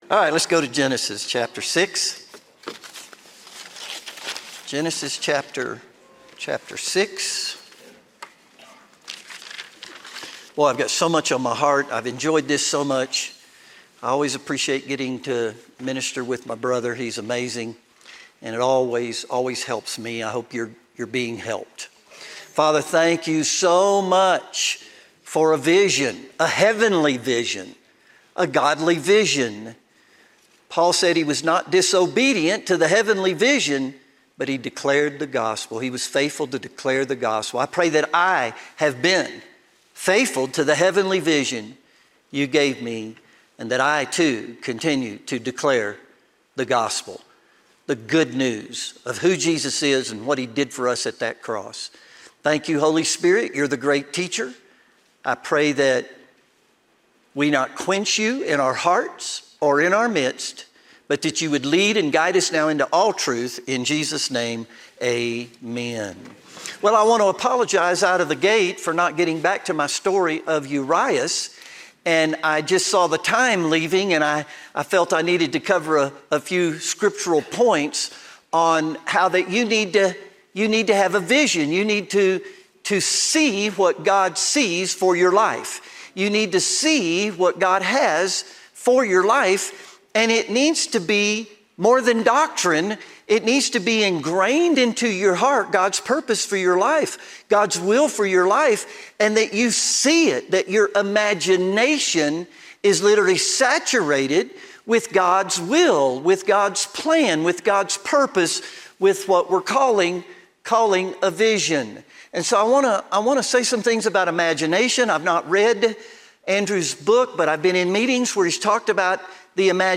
All advertisements are placed at the very beginning of the episode so nothing interrupts the experience once the story begins.